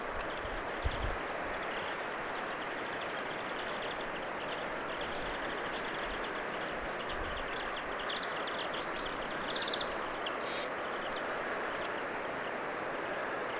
viherpeippojen ääniä linturuokinnalla
viherpeippojen_aania_linturuokinnalla.mp3